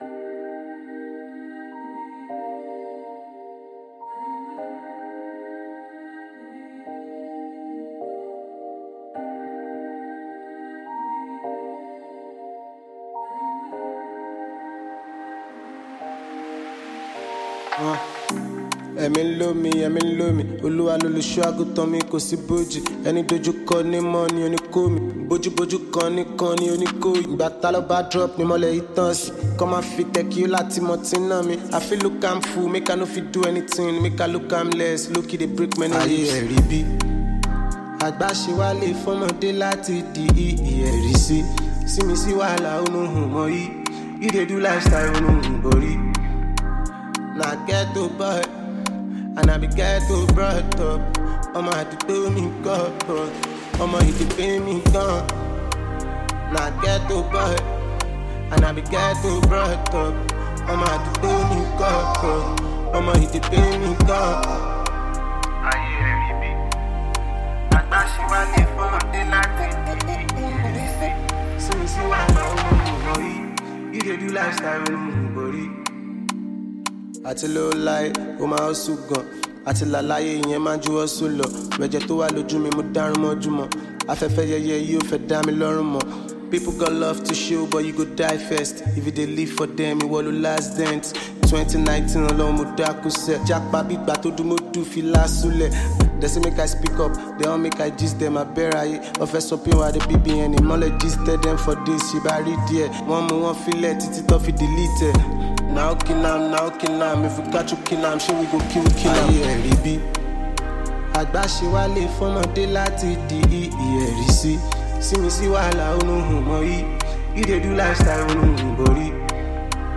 Well renowned Nigerian rapper, singer, songwriter
gbedu